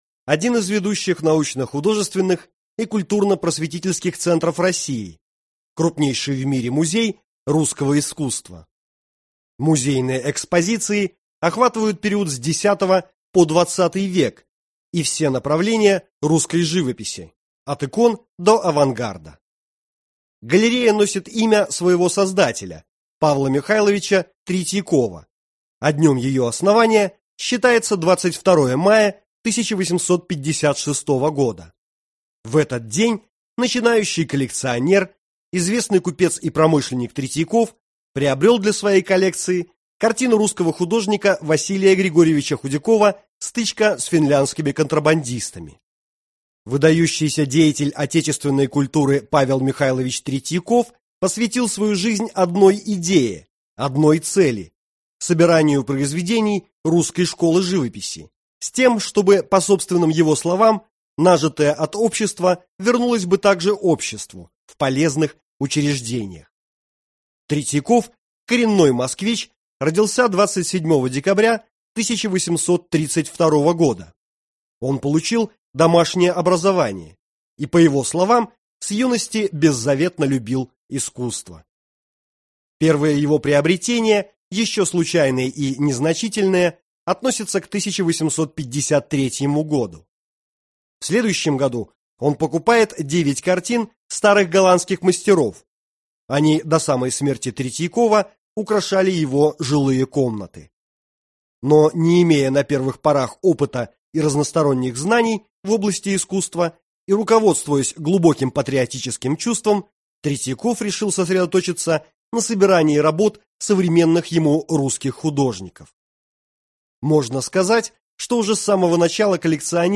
Аудиокнига Путеводитель по Третьяковской галерее | Библиотека аудиокниг